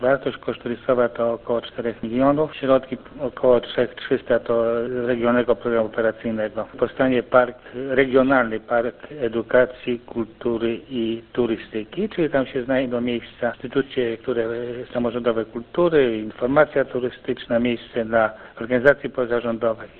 Jak informuje Radio 5 Józef Karpiński, burmistrz Rynu, znajdą tam miejsce wszystkie instytucje kultury działające w mieście oraz organizacje pozarządowe.